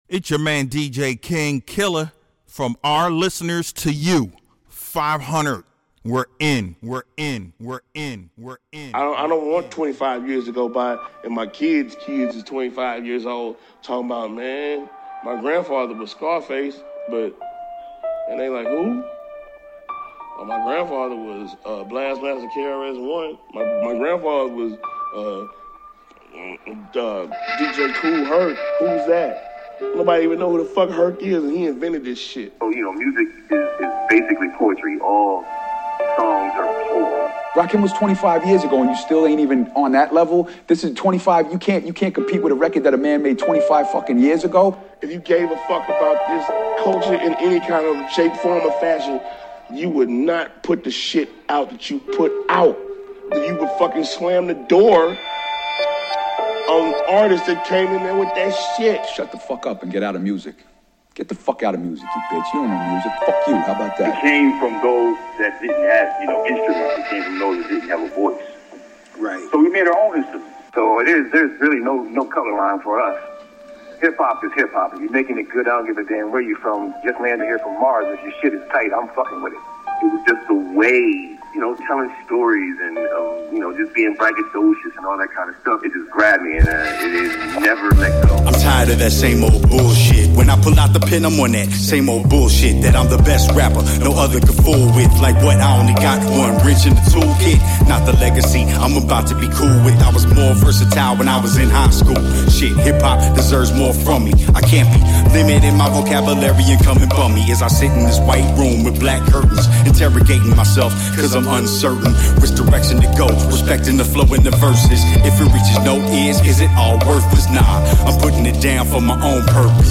We promised a mixtape, and now we’re delivering a mixtape.